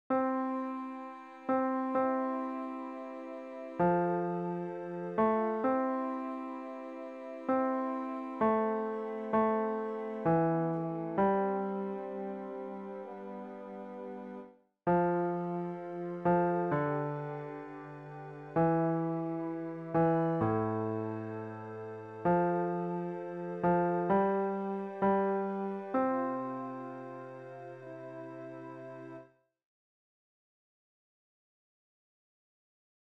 Chorproben MIDI-Files 514 midi files